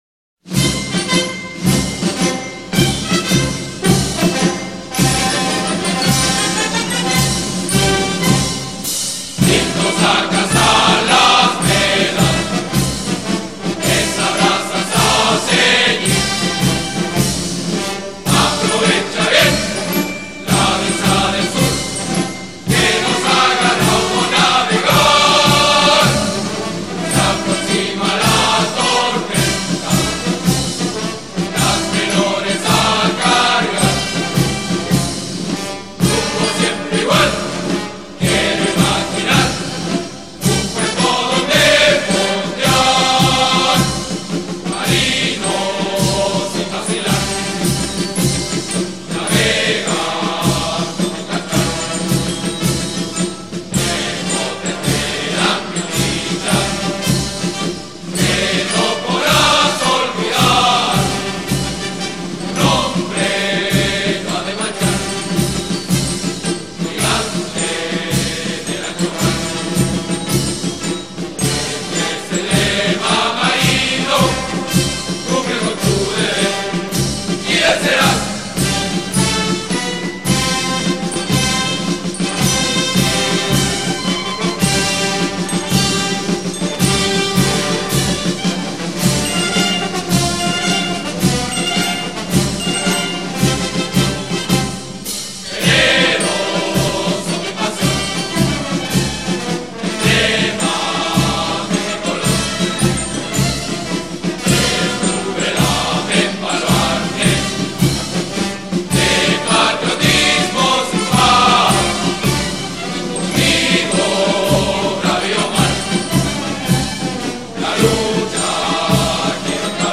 'Brazas a Ceir'  ( Himno de la Armada de Chile)   Himnos y Marchas Militares de Chile.mp3